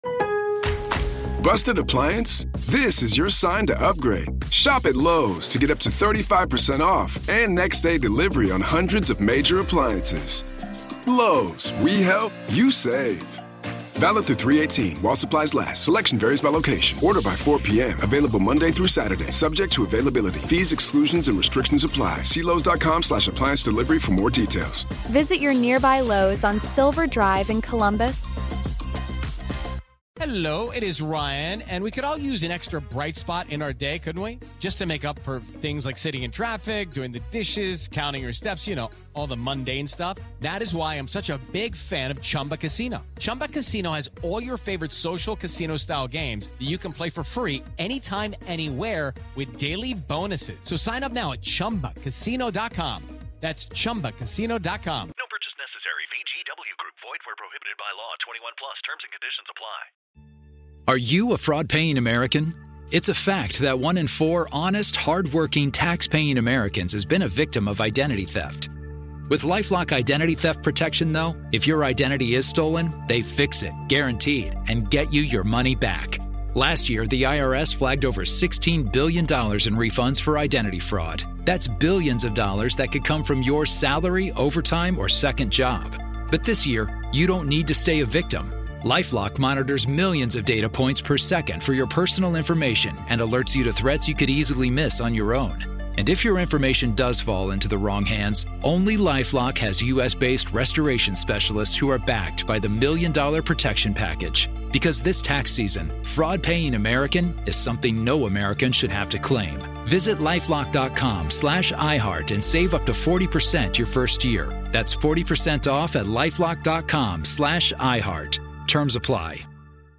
In this conversation